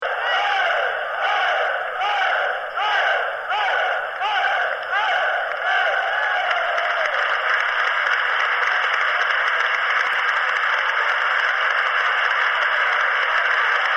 Hey Hey Aplaudissement 219 ko MP3
heyheyapplaus[1].mp3